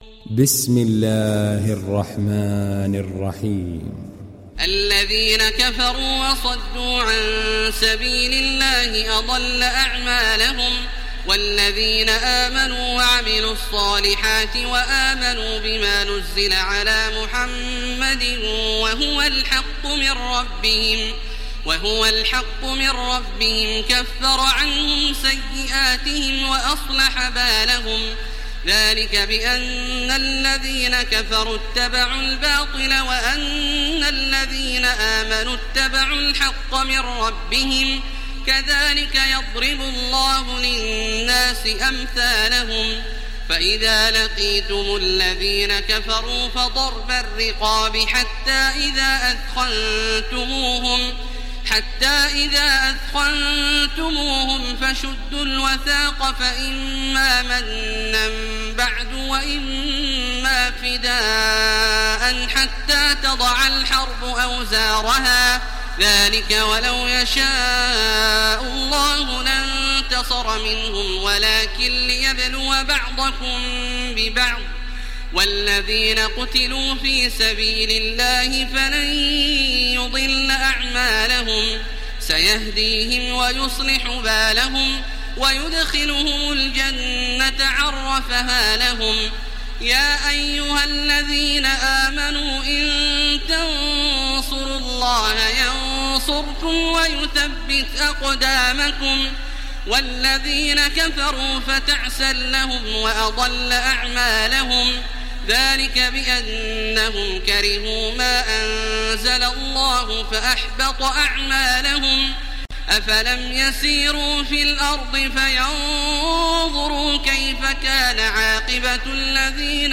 دانلود سوره محمد تراويح الحرم المكي 1430